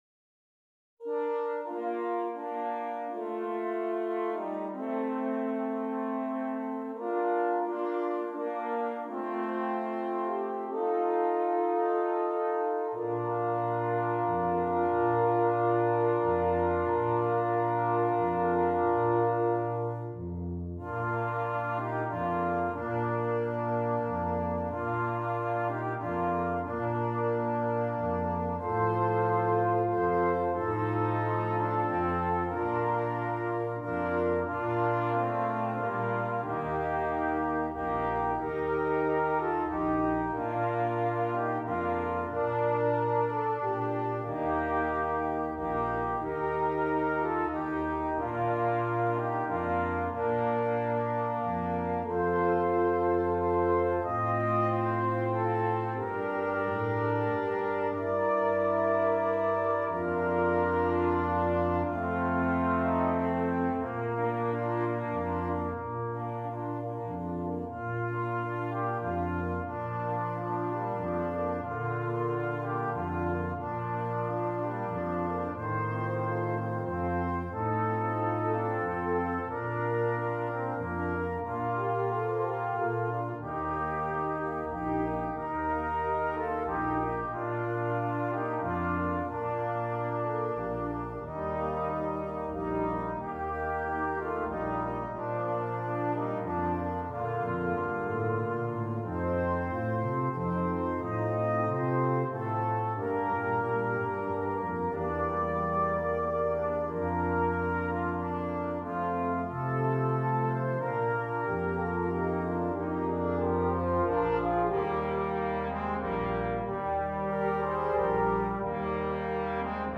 Christmas
Brass Quintet